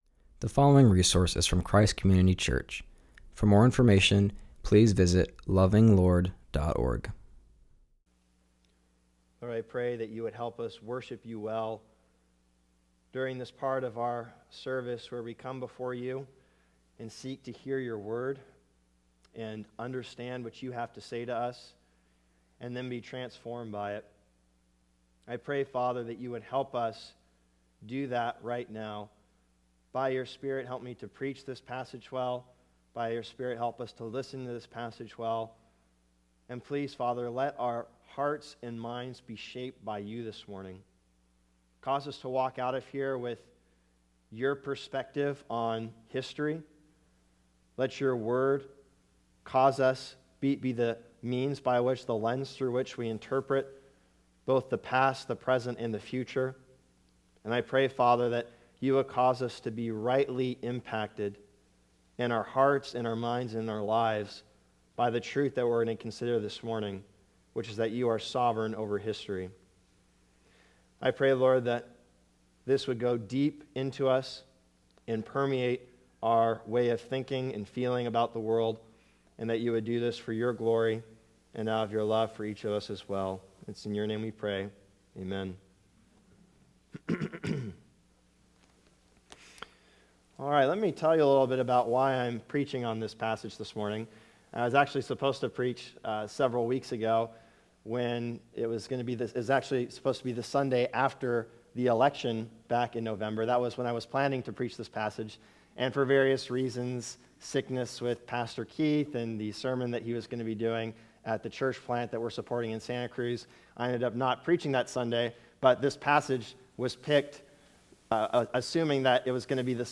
preaches from Daniel 2:21.